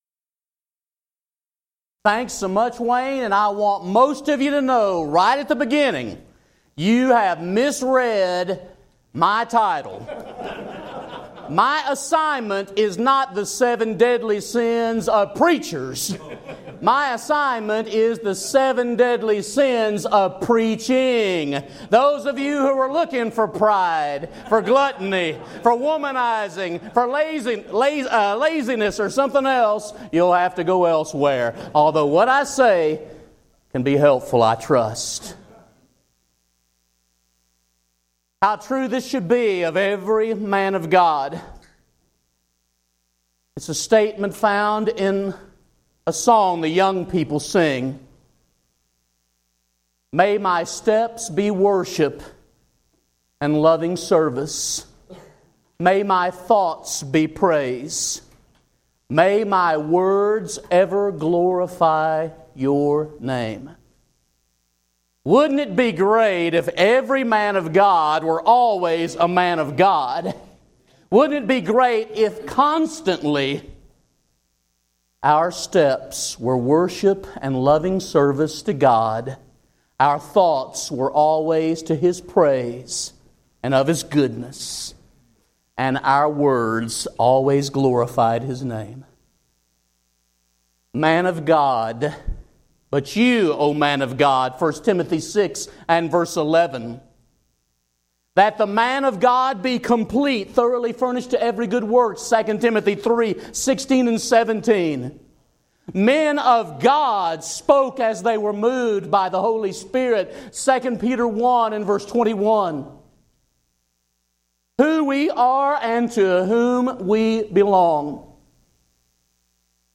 Event: 2014 Focal Point
this lecture